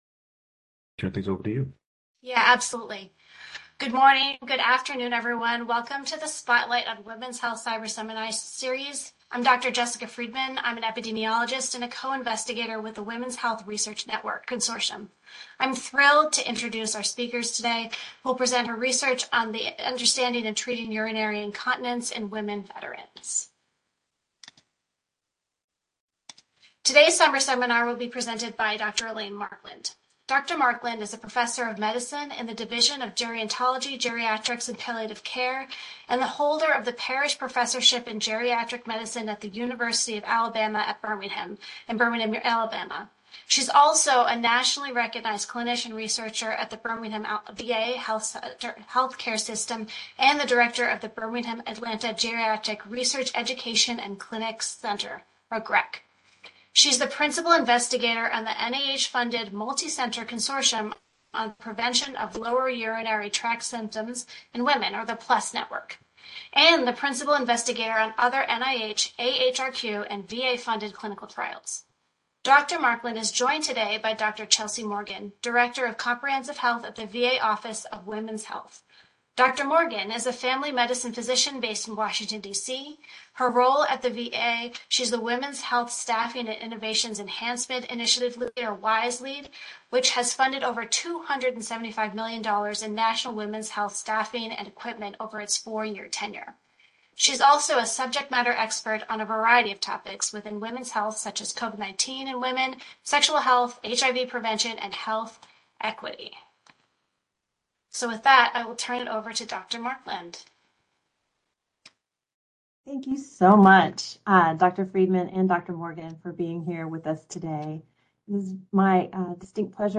MD Seminar date